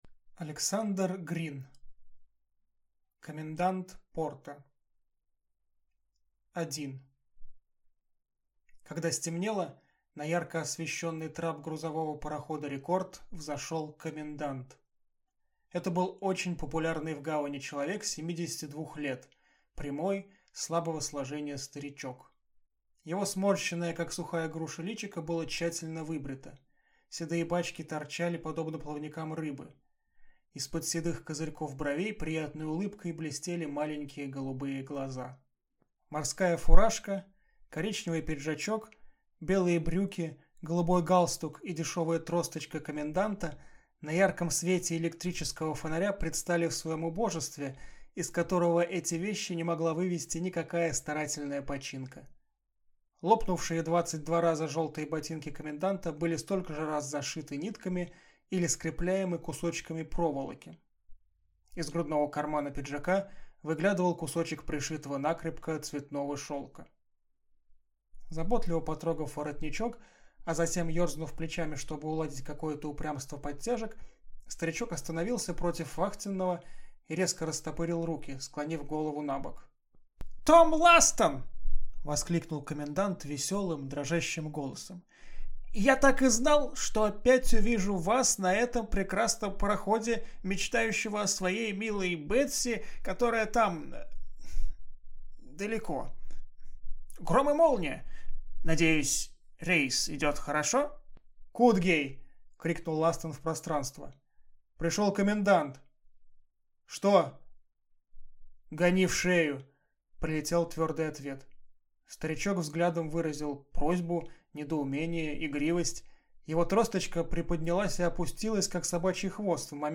Аудиокнига Комендант порта | Библиотека аудиокниг